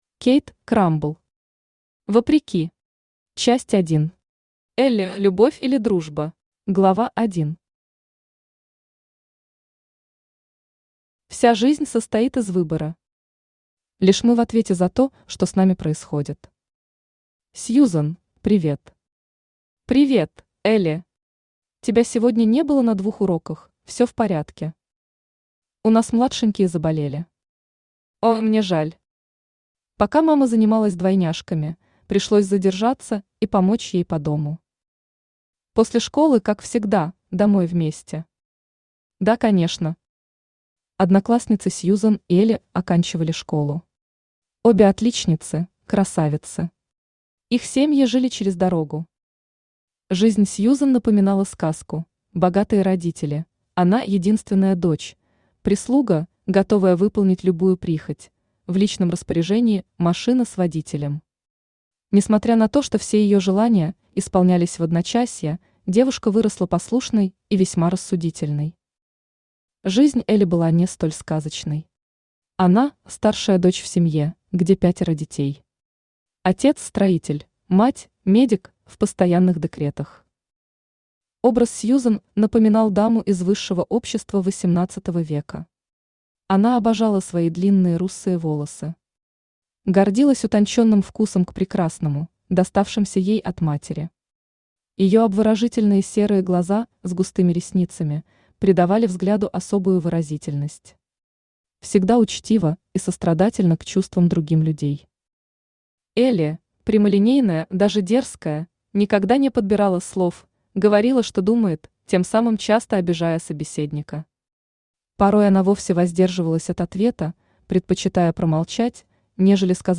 Автор Кейт Крамбл Читает аудиокнигу Авточтец ЛитРес.